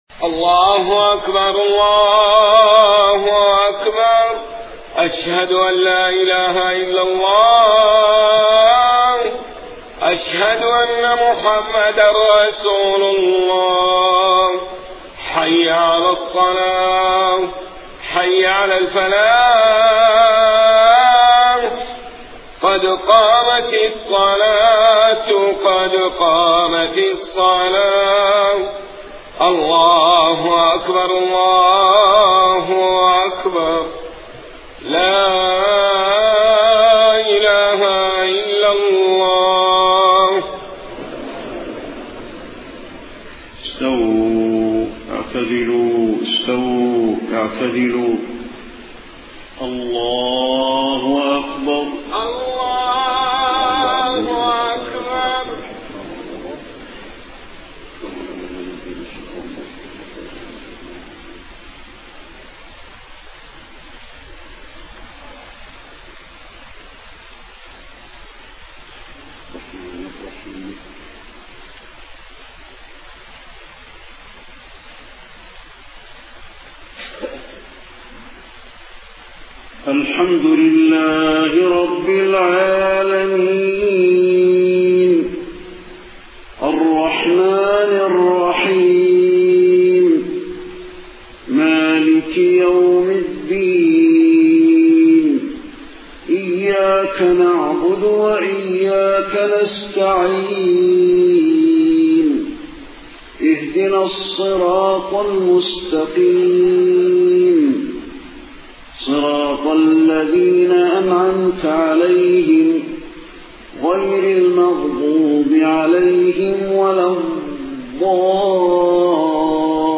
صلاة العشاء 24 صفر 1431هـ فواتح سورة الذاريات 1-23 > 1431 🕌 > الفروض - تلاوات الحرمين